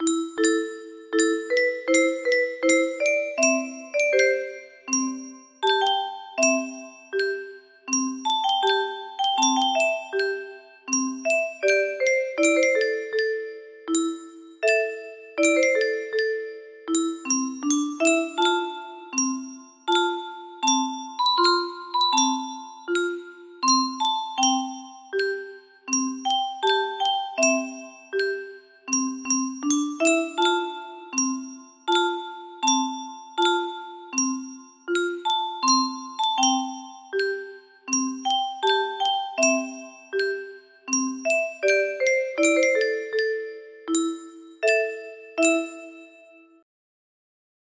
El condor pasa music box melody